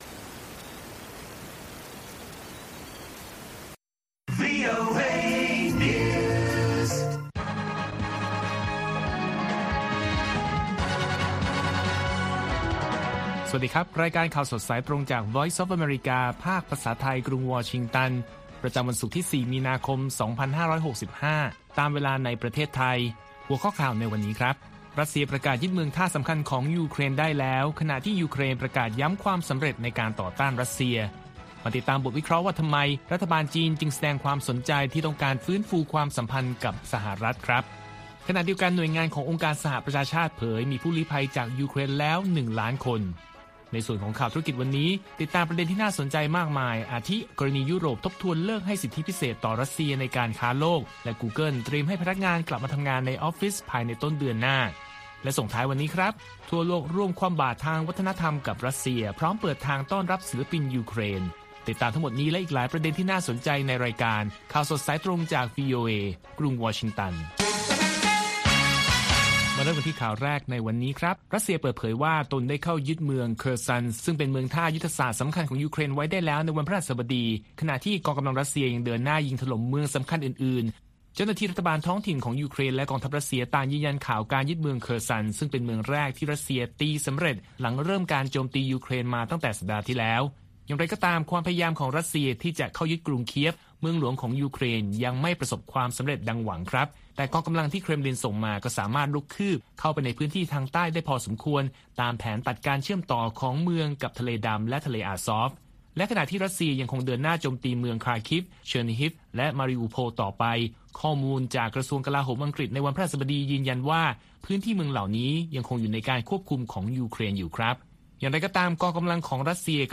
ข่าวสดสายตรงจากวีโอเอ ภาคภาษาไทย ประจำวันศุกร์ที่ 4 มีนาคม 2565 ตามเวลาประเทศไทย